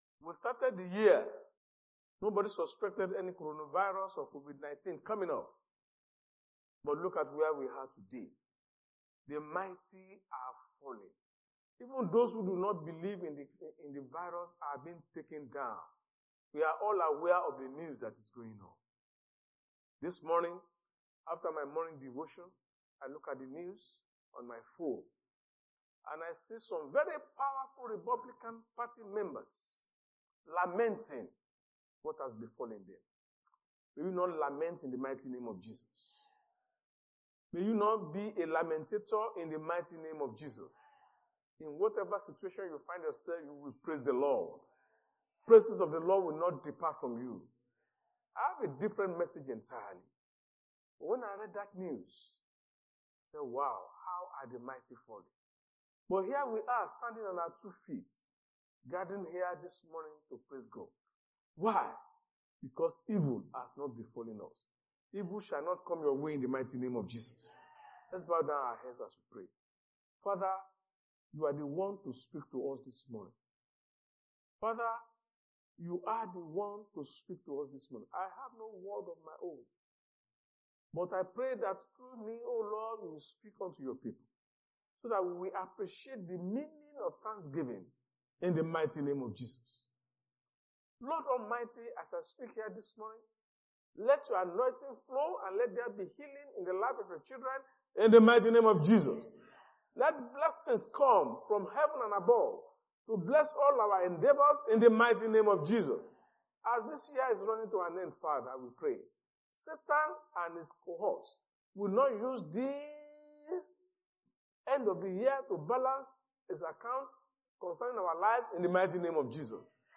Sunday Sermon: For His Mercies Endures Forever
Service Type: Sunday Church Service